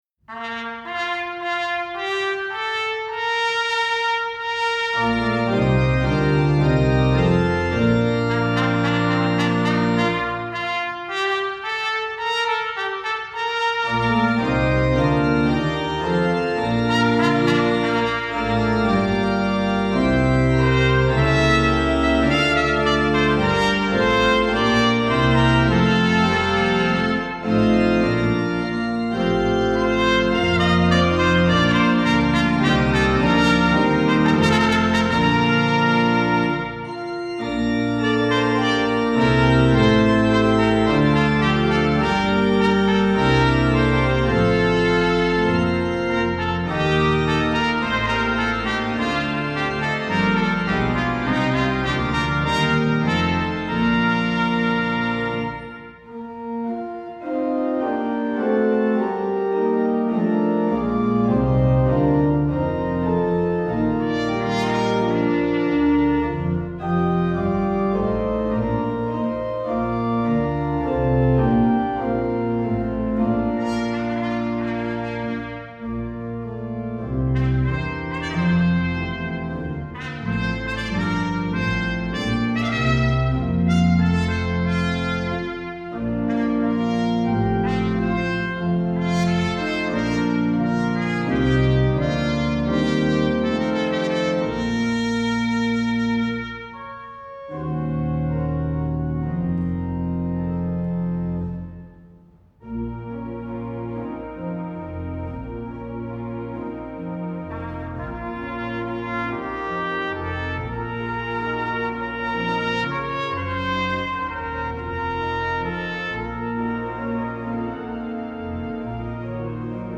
Voicing: Trumpet w/keyboard